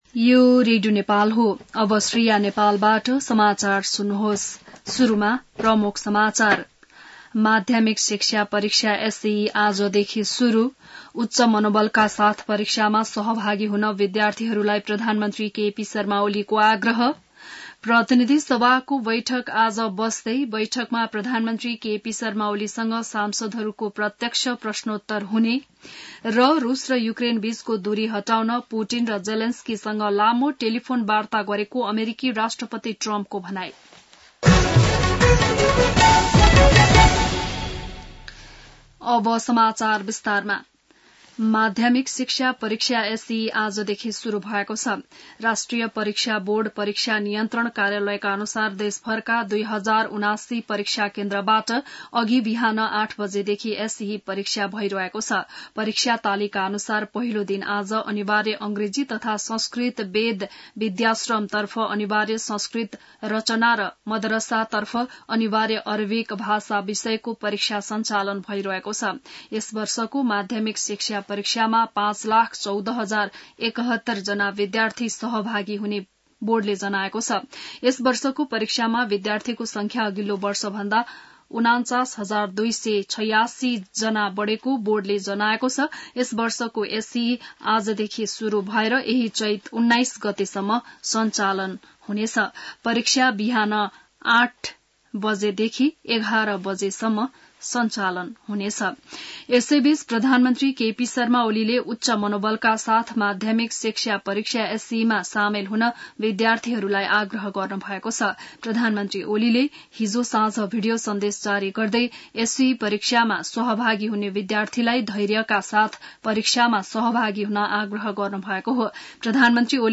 बिहान ९ बजेको नेपाली समाचार : ७ चैत , २०८१